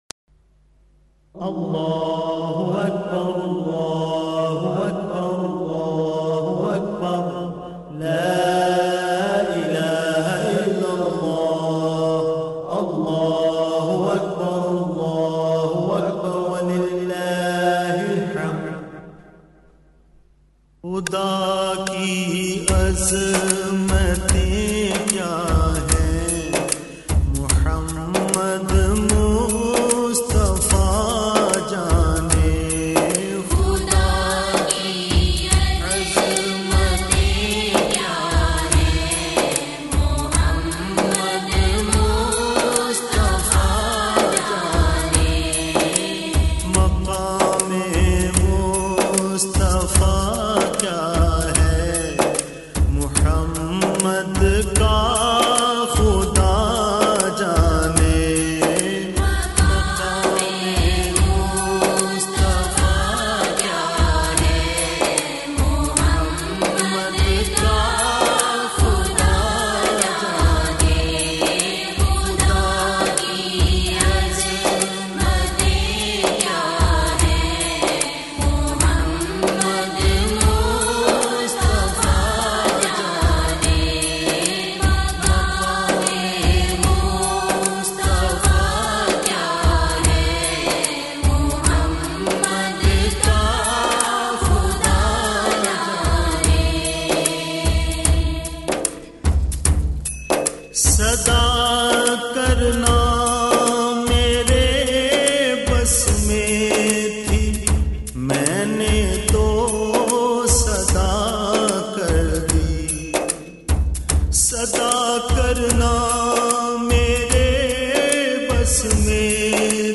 Naats